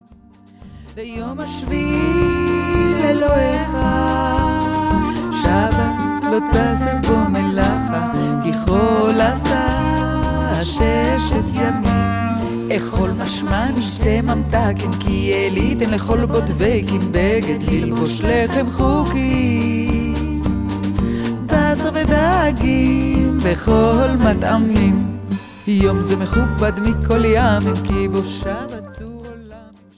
In her warm, deep voice